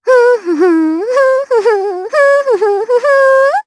Annette-Vox_Hum_jp.wav